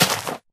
Sound / Minecraft / dig / grass2